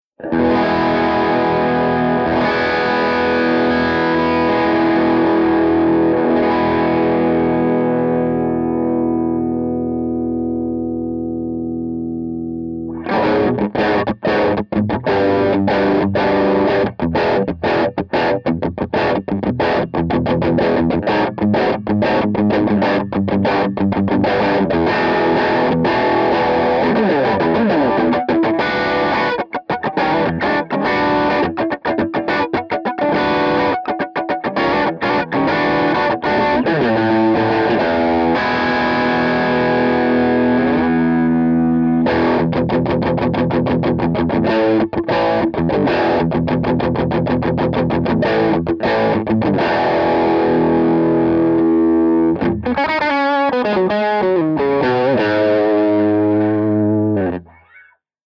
010_AC30_DRIVE_SC
010_AC30_DRIVE_SC.mp3